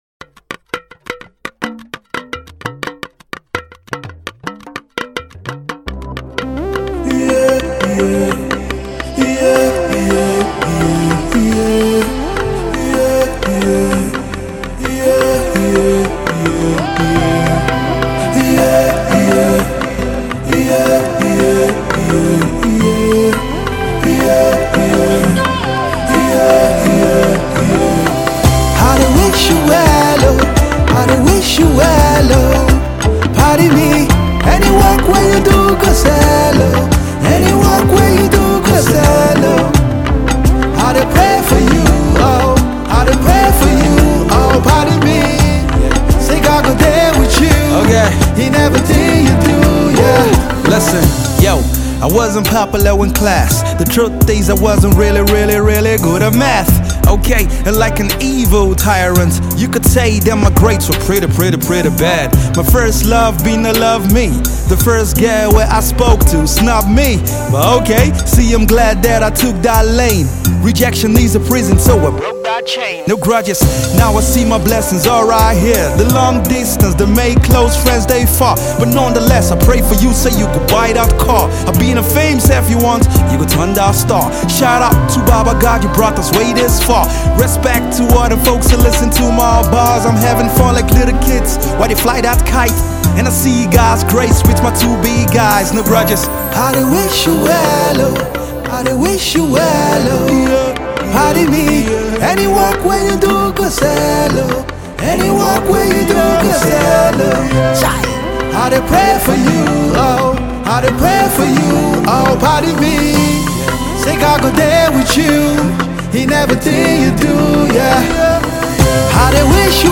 Christian Rapper